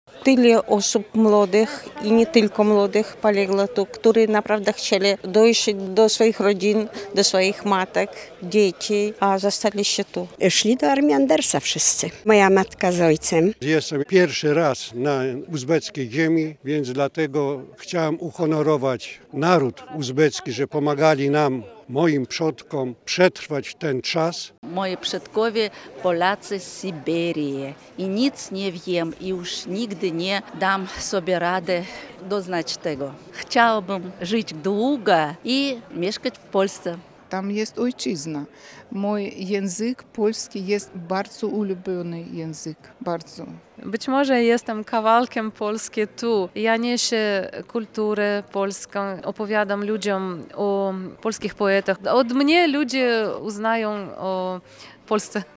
Posłuchaj wypowiedzi uczestników uroczystości: